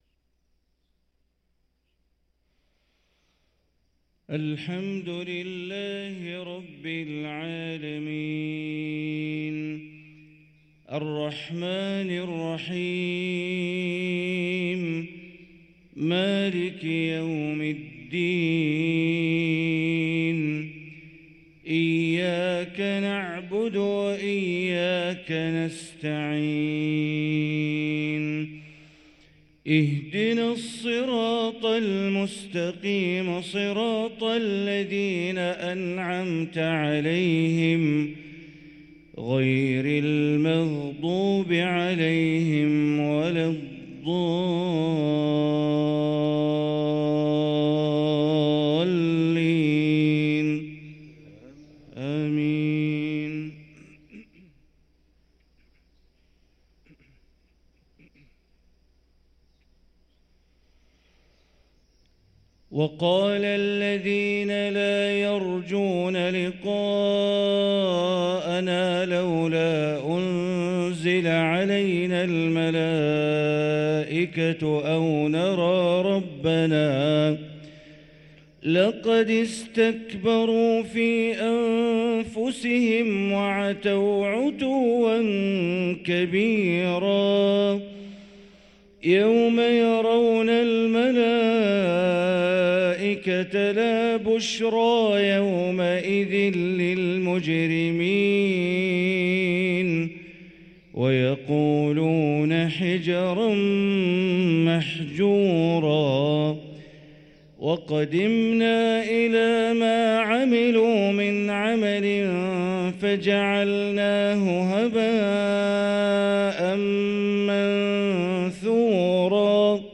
صلاة الفجر للقارئ بندر بليلة 28 رجب 1444 هـ
تِلَاوَات الْحَرَمَيْن .